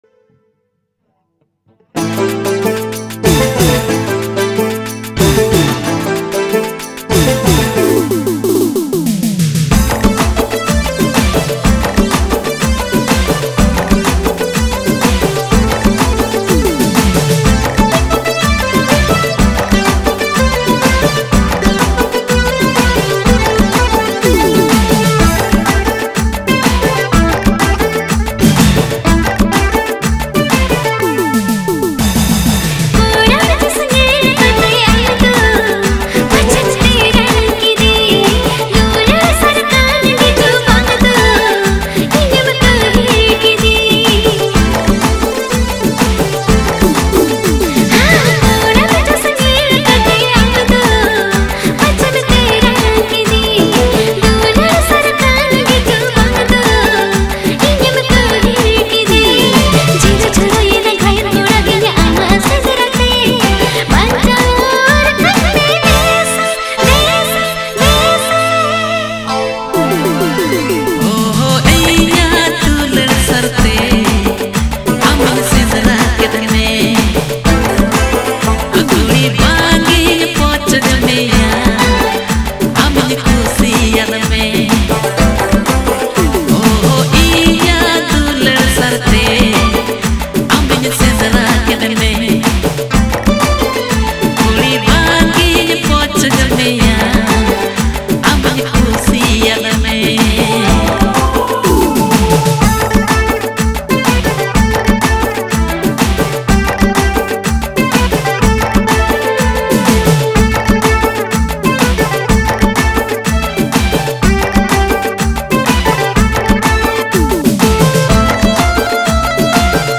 Santali Gana